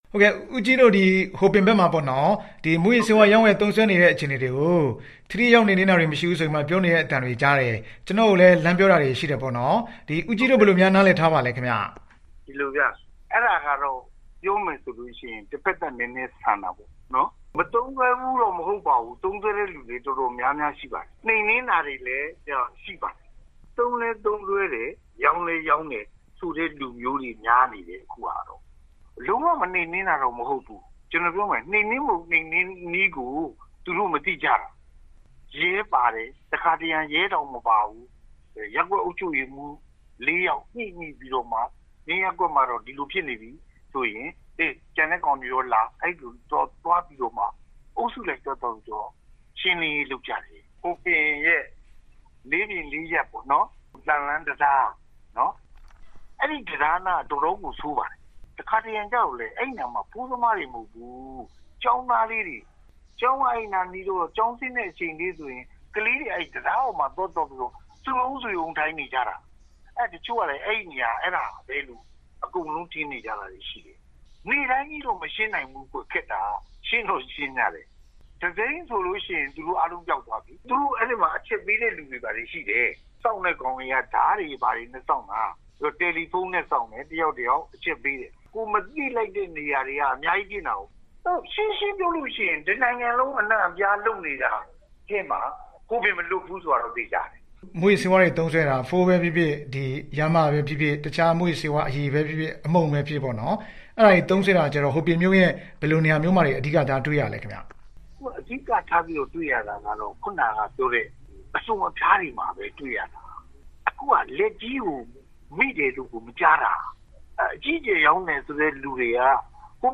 ဒီကနေ့ ဆက်သွယ်မေးမြန်းထားပါတယ်။